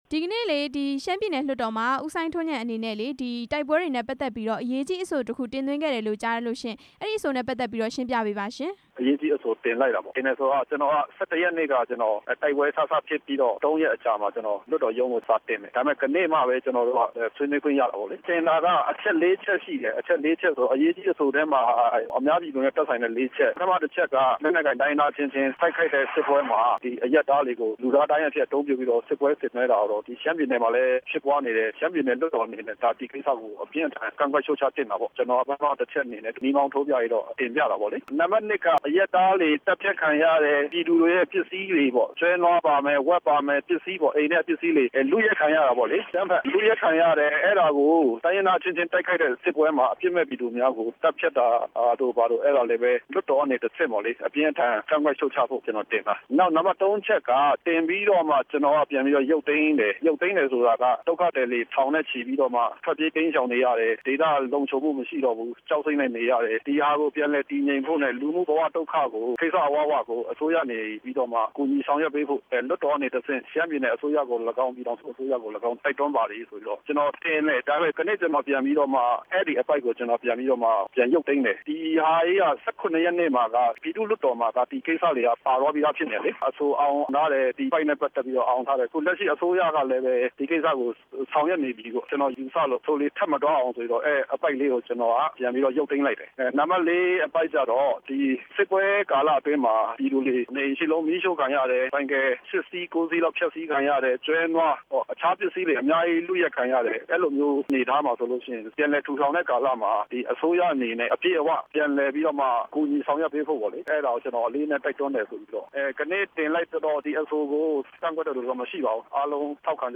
အရေးကြီးအဆို တင်သွင်းခဲ့တဲ့ ရှမ်းတိုင်းရင်းသားများ ဒီမိုကရေစီအဖွဲ့ချုပ် ပြည်နယ်လွှတ်တော် ကိုယ်စားလှယ် ဦးစိုင်းထွန်းဉာဏ် ကို RFA သတင်းထောက်